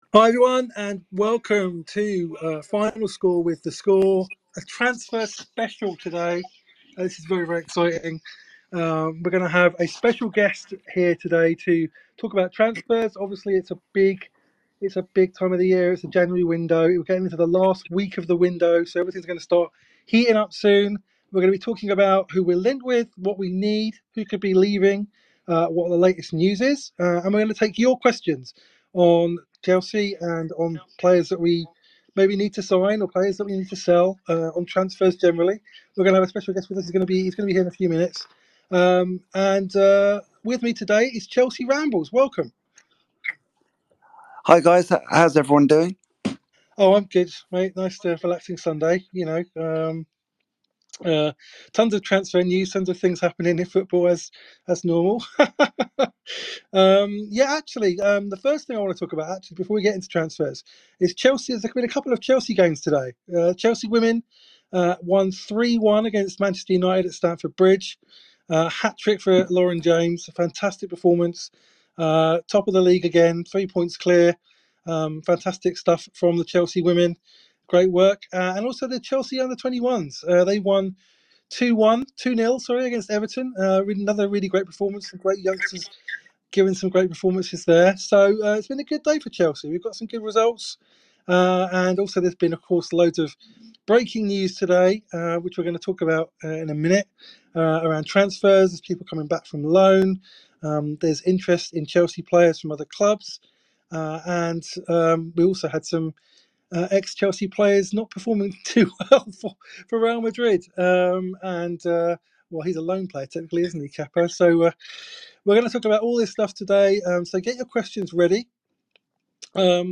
Welcome to a special call-in show with The Chelsea Echo!